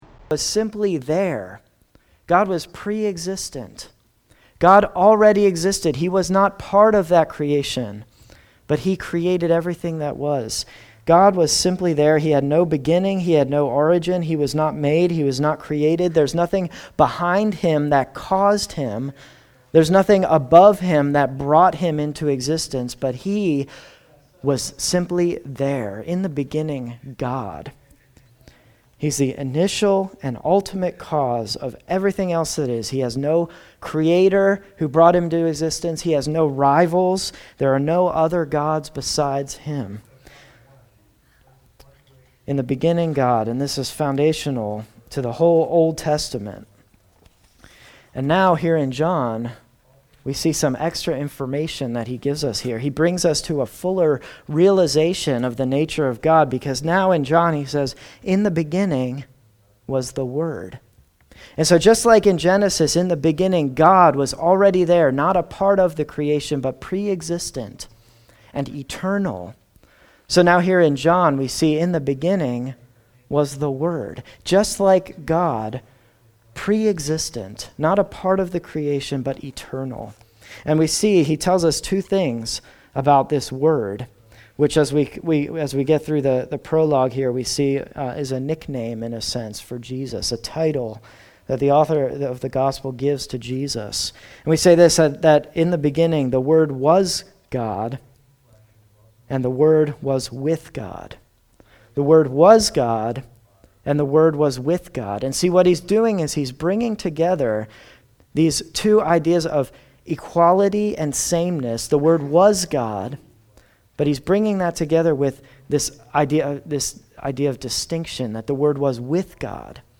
[The beginning of this sermon was not recorded] This text is John’s famous prologue, which tells us 3 things about Jesus: 1) Who he is – the eternal, fully divine, Son of God, who became flesh and dwelt among us; 2) Why he came – to bring light into darkness and life into death; and 3) How the world responded – ultimately, there are only 2 responses to Jesus: acceptance or rejection.